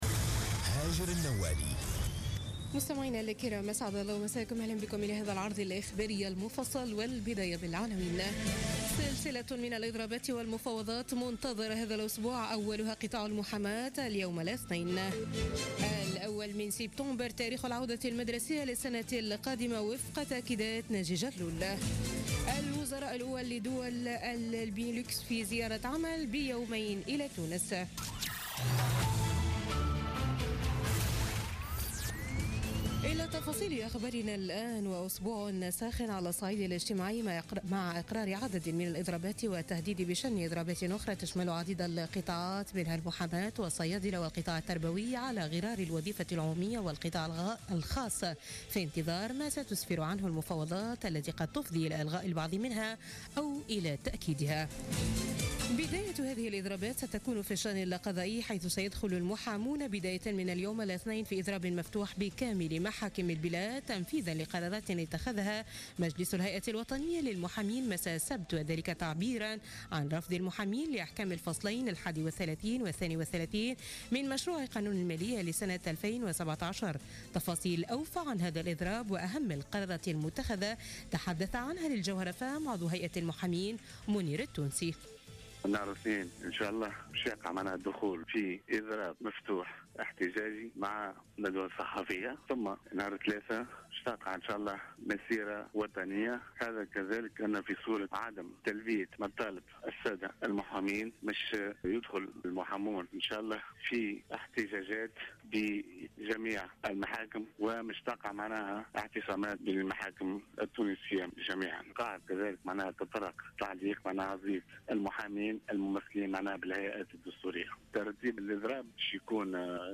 نشرة أخبار منتصف الليل ليوم الإثنين 5 ديسمبر2016